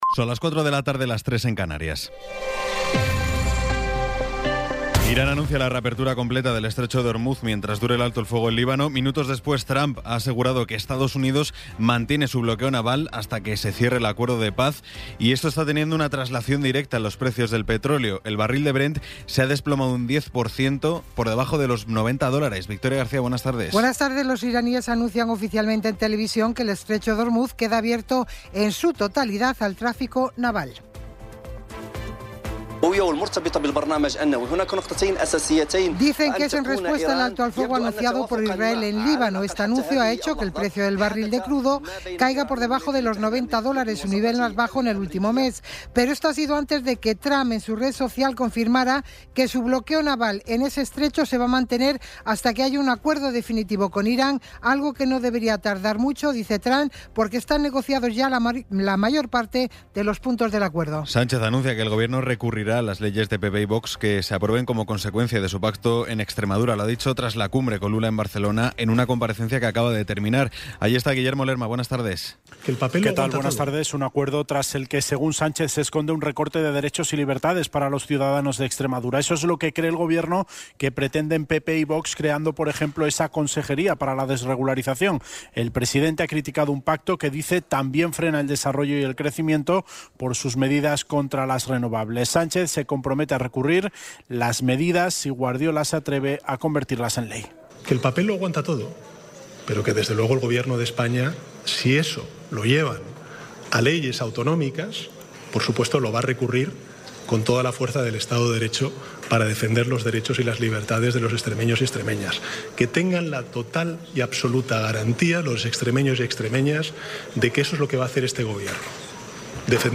Resumen informativo con las noticias más destacadas del 17 de abril de 2026 a las cuatro de la tarde.